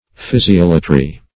Search Result for " physiolatry" : The Collaborative International Dictionary of English v.0.48: Physiolatry \Phys`i*ol"a*try\, n. [Gr. fy`sis nature + ? service.] The worship of the powers or agencies of nature; materialism in religion; nature worship.